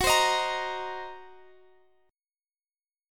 Listen to F#6b5 strummed